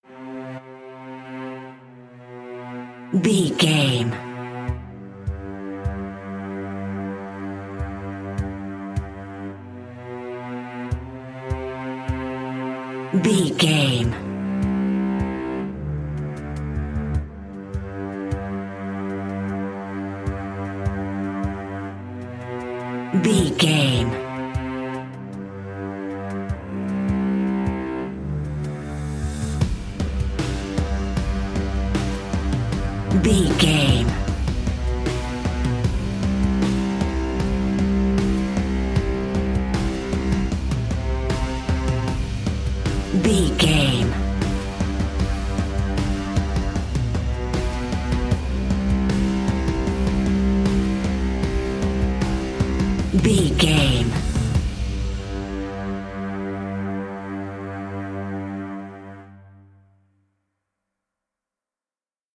In-crescendo
Ionian/Major
Slow
dreamy
melancholy